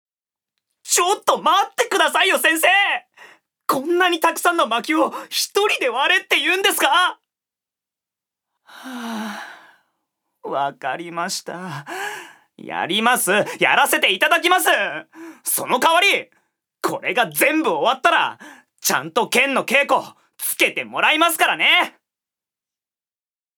所属：男性タレント
セリフ４